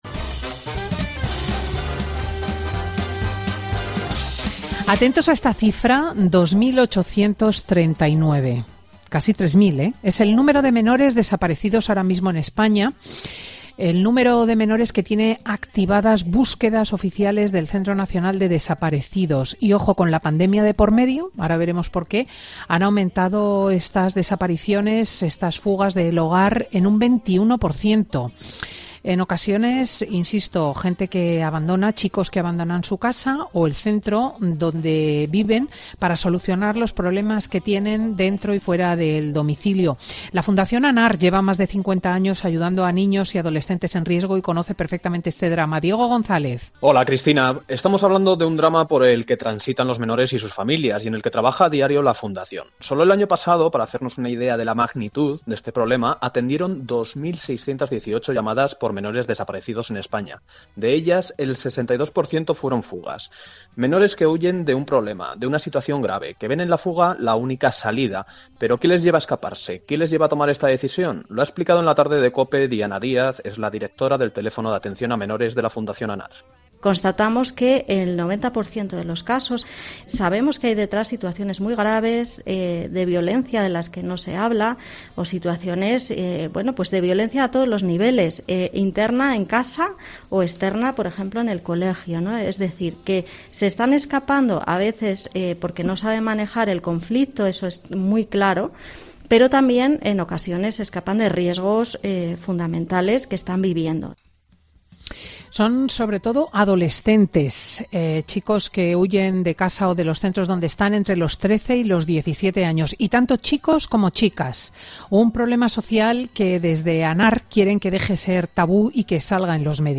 El juez de menores de Granada cuenta en Fin de Semana con Cristina los casos con los que se encuentra en el juzgado